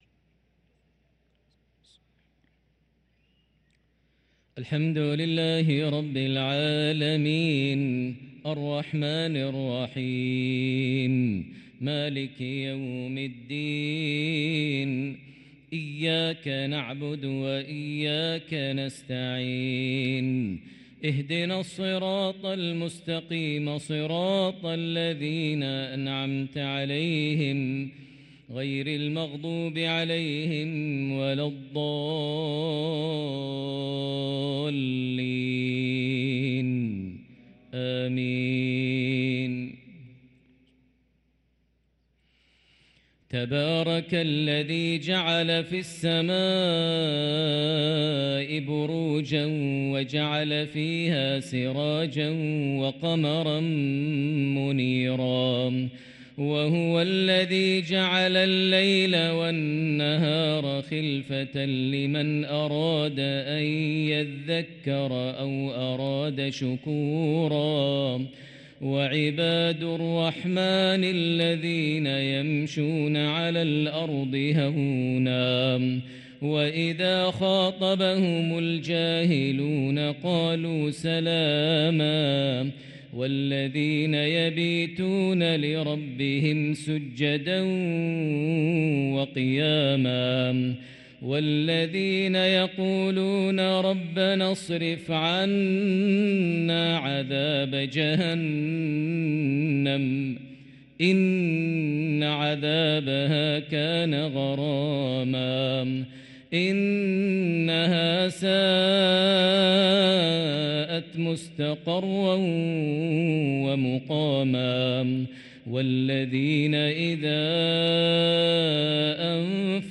صلاة العشاء للقارئ ماهر المعيقلي 4 ربيع الآخر 1445 هـ
تِلَاوَات الْحَرَمَيْن .